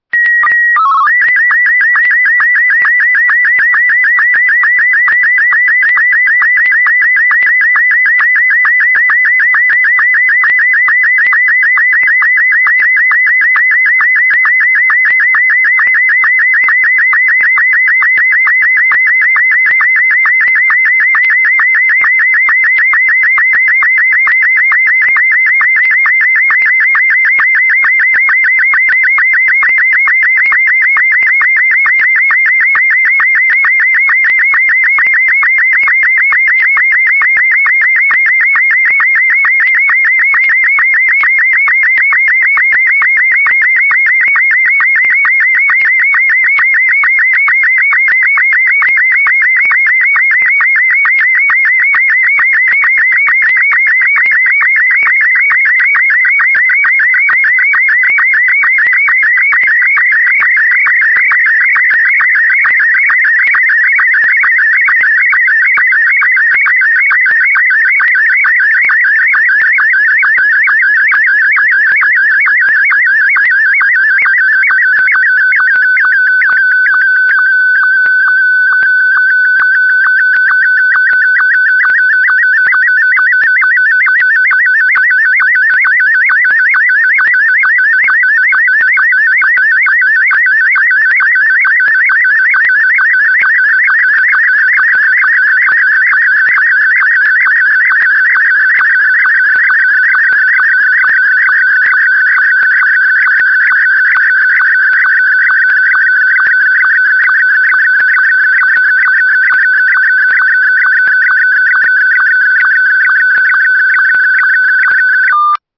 Imágenes SSTV
Cada pixel se codifica en un tono (como las teclas de un piano) y la frecuencia del tono (la nota musical) se mueve hacia arriba o hacia abajo para designar el brillo y el color del pixel.
El audio resultante de la codificación se transmite a la Tierra por a través de ondas de radio.
SSTV_sunset_audio.ogg.mp3